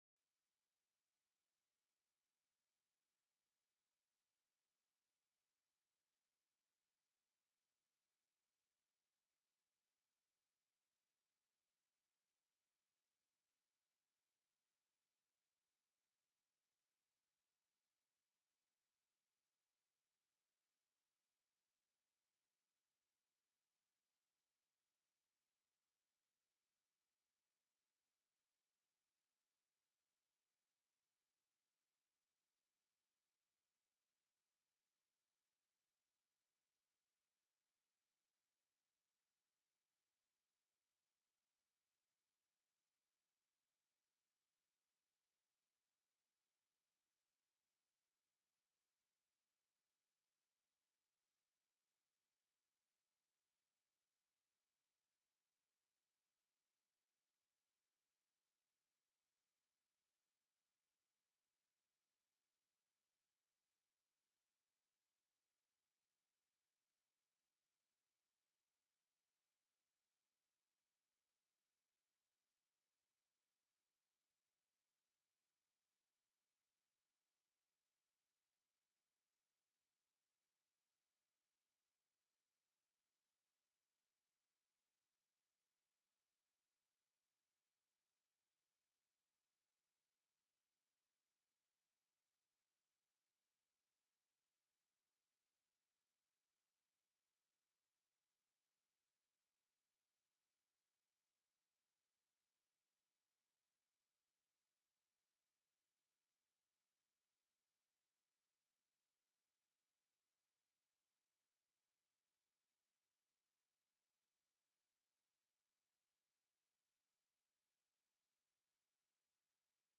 The audio recordings are captured by our records offices as the official record of the meeting and will have more accurate timestamps.
Presentation(s): Winter Road Maintenance, Department of Transportation and Public Facilities (dotpf)